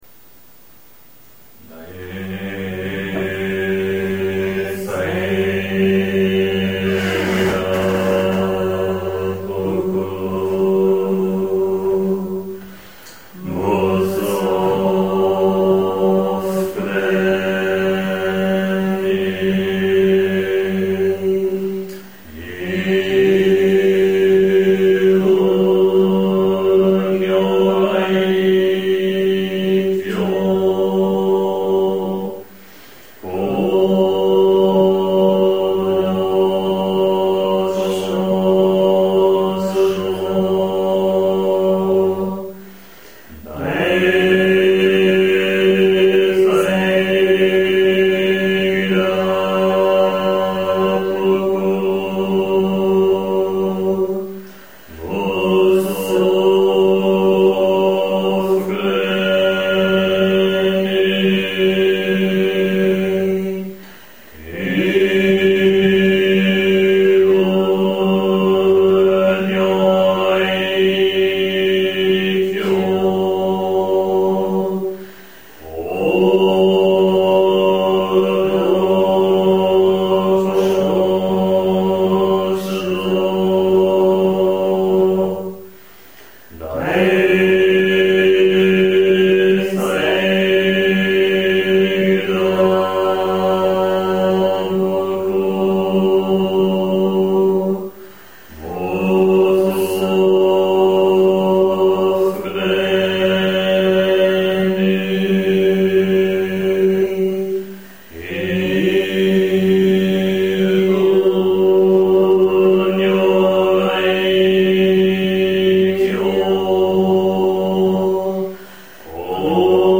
Das Kesa-Sūtra wird morgens rezitiert, wenn nach dem Zazen das Kesa, das buddhistische Gewand, welches Personen, die die Laien- oder Nonnen Mönchs-Ordination erhalten haben, angelegt wird.